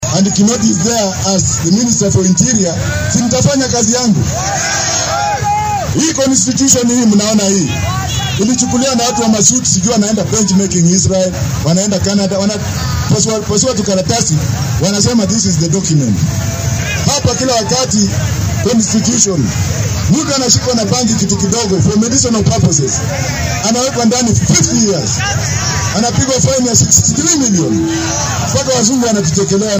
Xilli uu isku soo bax siyaasadeed ka sameeyay ismaamulka Meru ayuu Wajackoyah difaacay waxqabadka Kinoti.